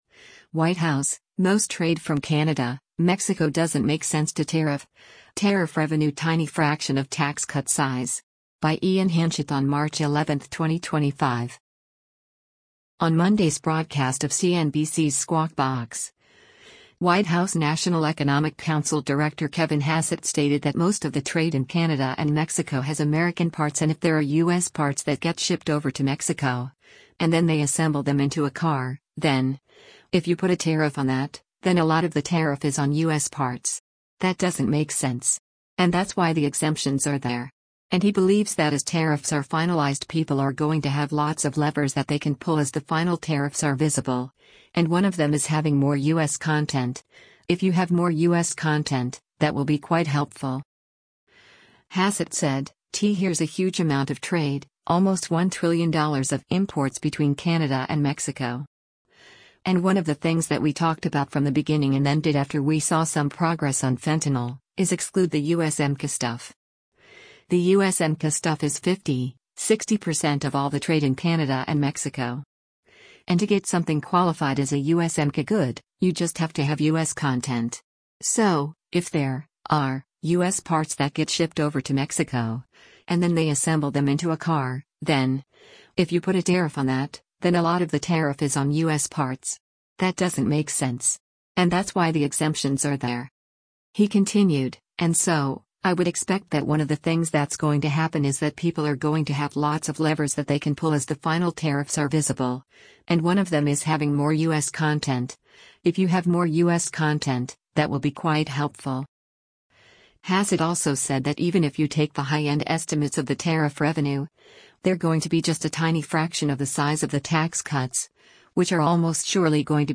On Monday’s broadcast of CNBC’s “Squawk Box,” White House National Economic Council Director Kevin Hassett stated that most of the trade in Canada and Mexico has American parts and if there are “U.S. parts that get shipped over to Mexico, and then they assemble them into a car, then, if you put a tariff on that, then a lot of the tariff is on U.S. parts. That doesn’t make sense. And that’s why the exemptions are there.”